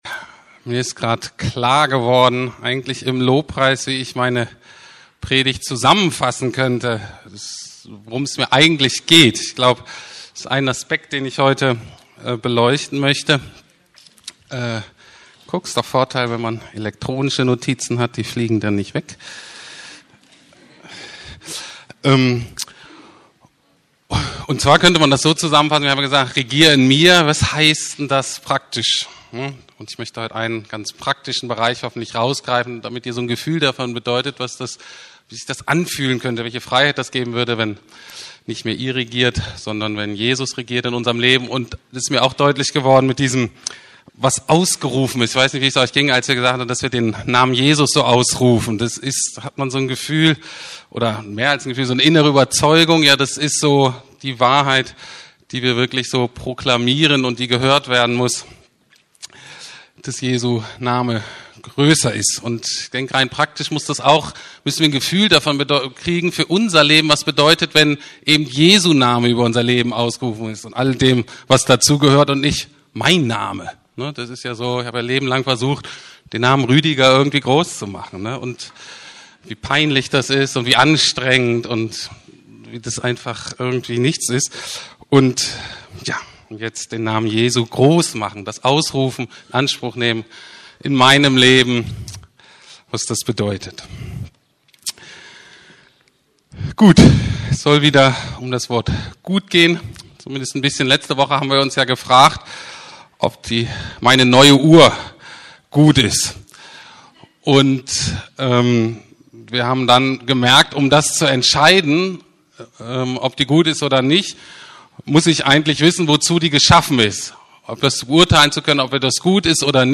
Das gute Werk Christi ~ Predigten der LUKAS GEMEINDE Podcast